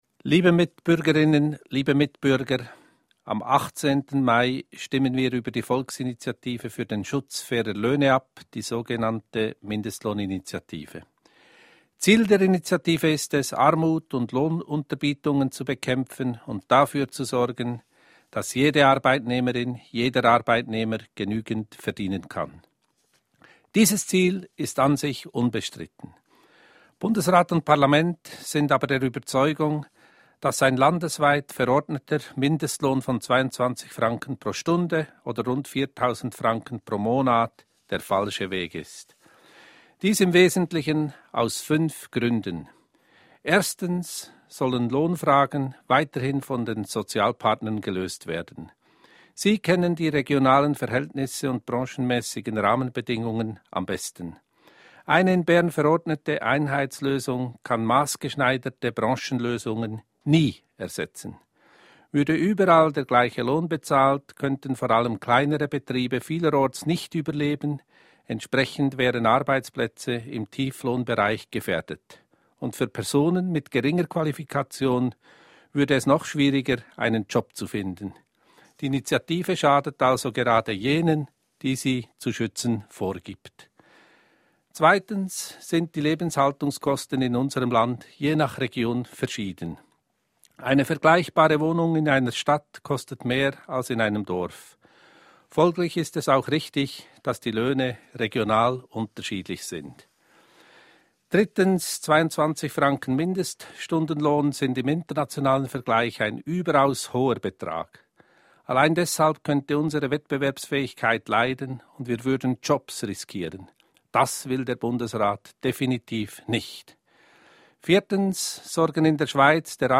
Bundesrat Johnann N. Schneider-Amman
Stellungnahme des Bundesrates